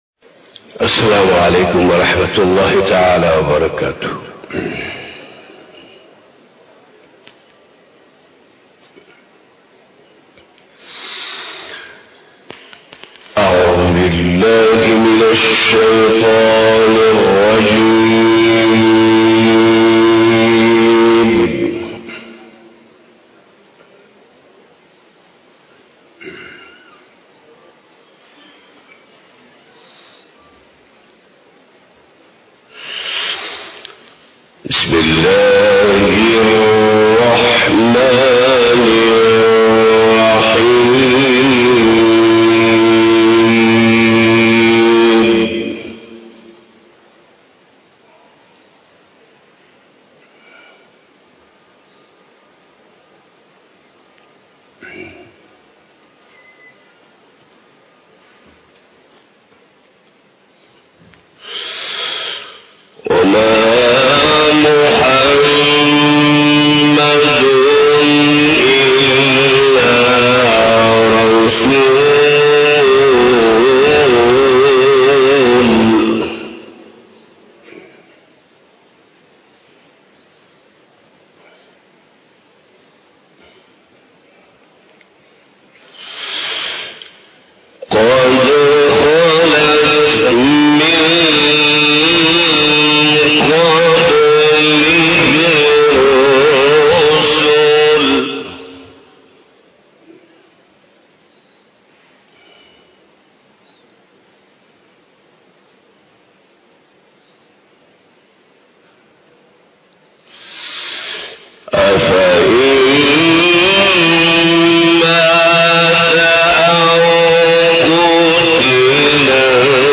Taqreeb E Khatm E Bukhari Shareef 01 March 2021 (16 Rajab 1442HJ) Monday
Bayan